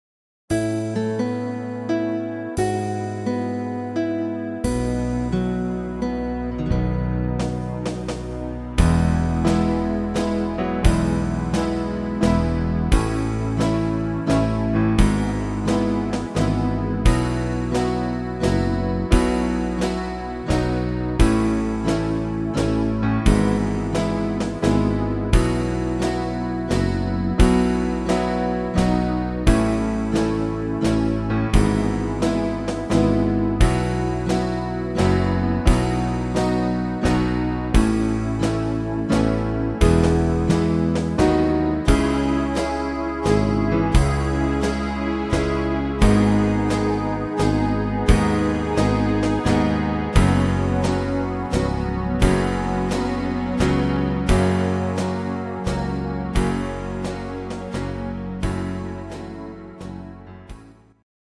Slow Waltz Version